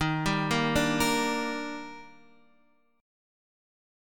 D#M7 chord